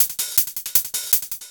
Hats 06.wav